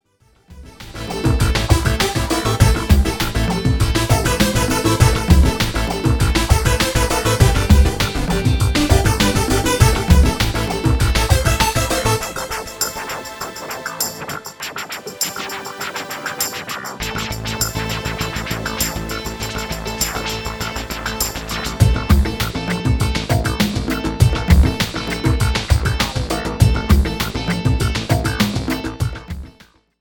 FUNK  (3.29)